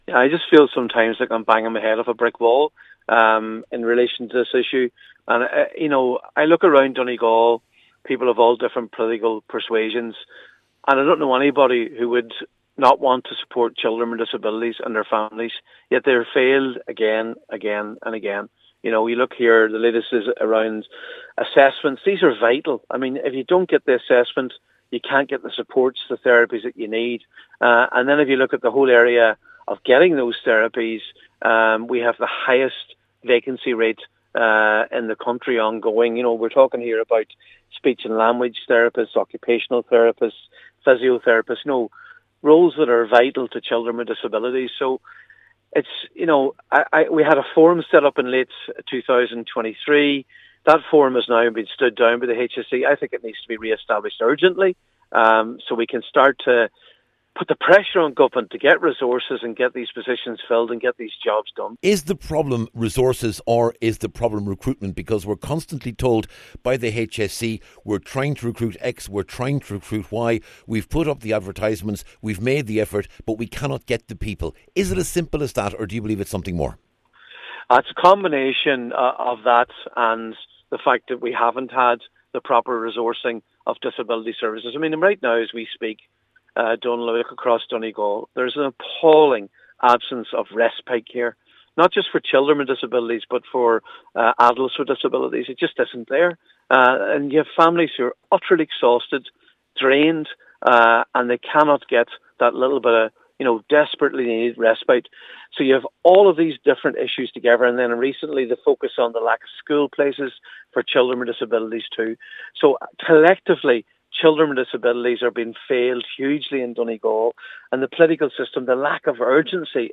Deputy MacLochlainn says it’s frustrating to be raising the same issues again and again………